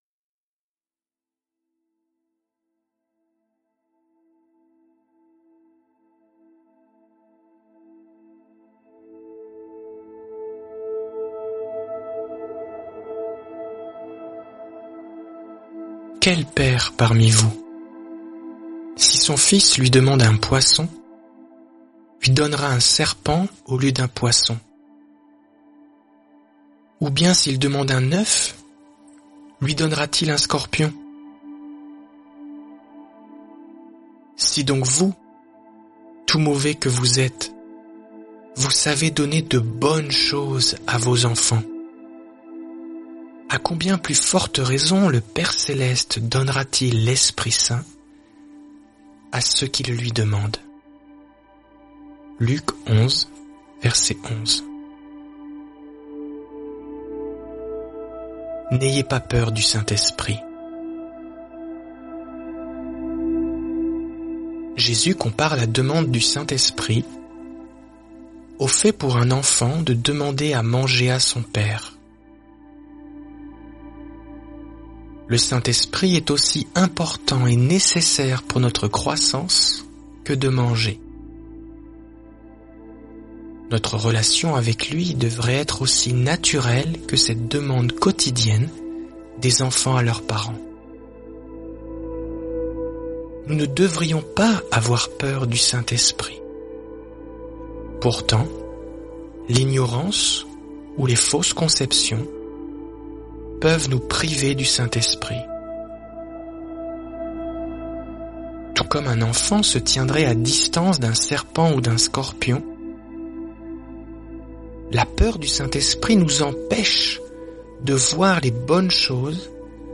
J’ai écrit un dialogue avec le Saint-Esprit dans la prière.